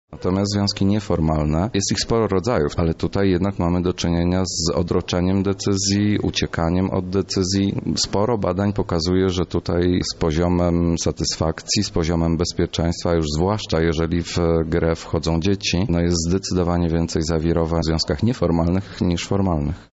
mówi psycholog